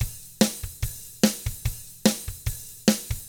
146ROCK T4-L.wav